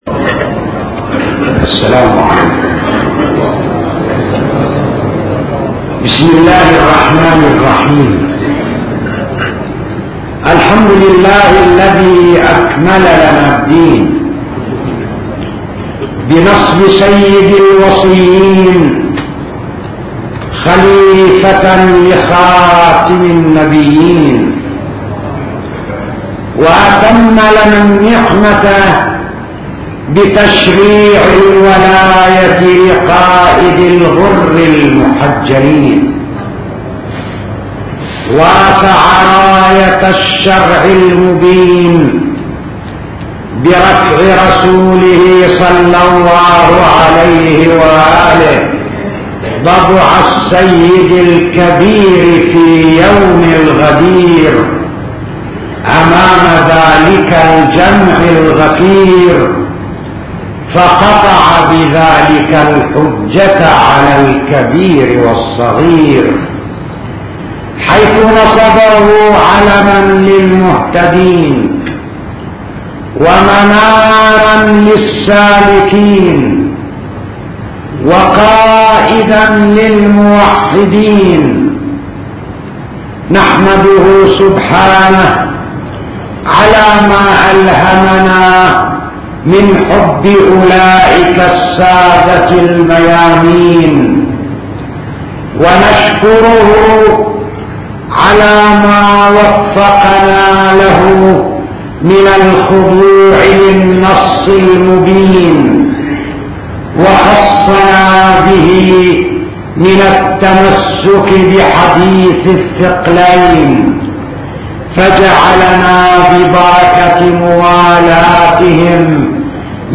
خطب